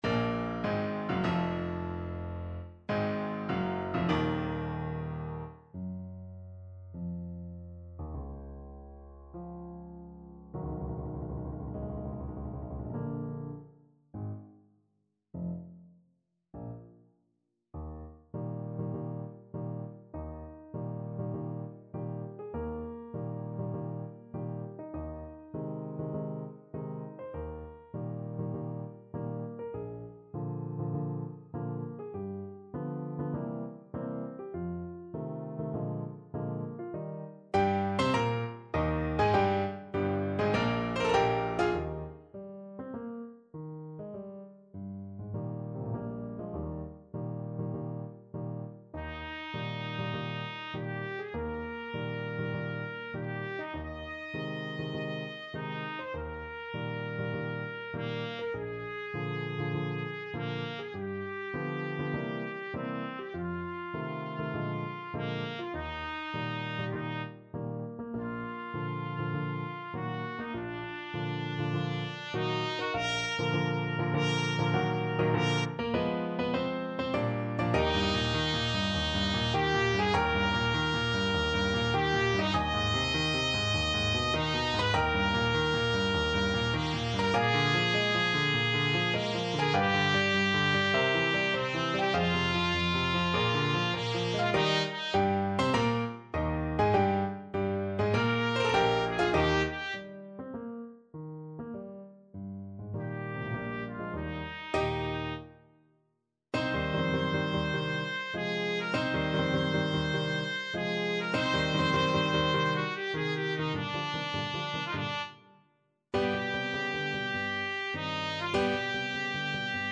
Trumpet version
4/4 (View more 4/4 Music)
Allegro maestoso (=100) (View more music marked Allegro)
Bb4-Ab6
Classical (View more Classical Trumpet Music)